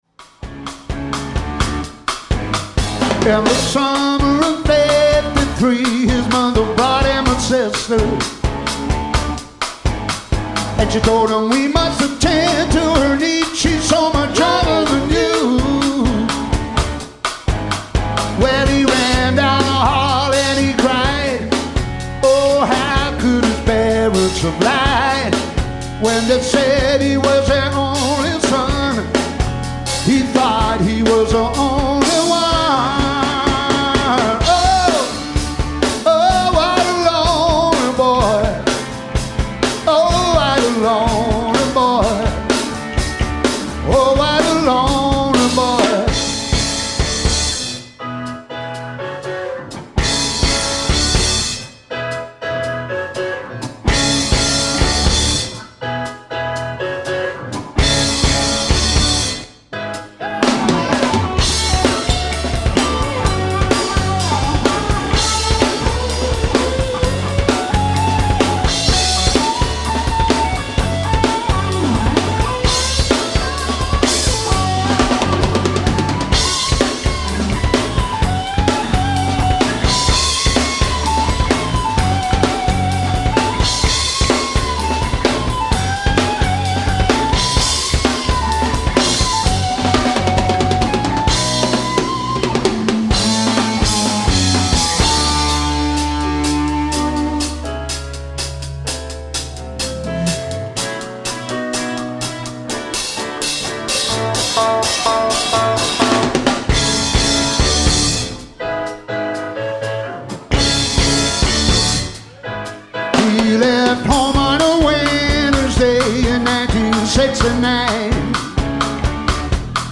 all songs recorded live 2009